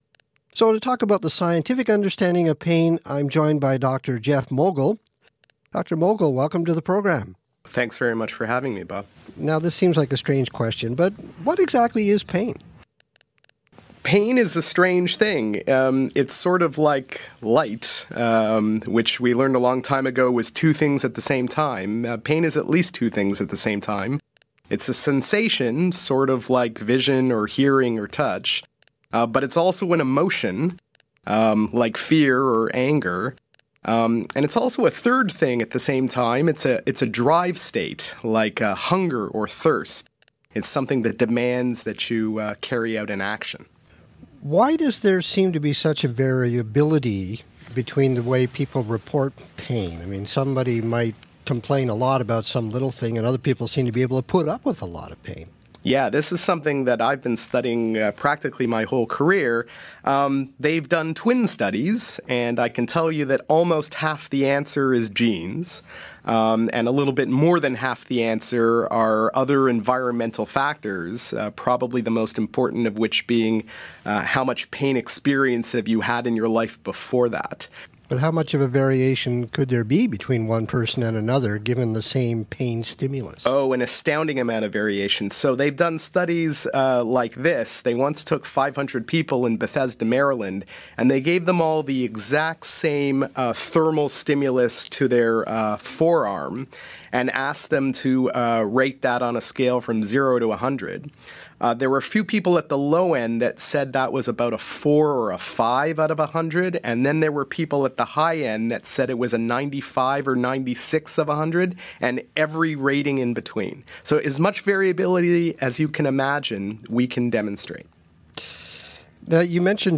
Accent: Canadian
TOEFL: A professor talks about the science of pain.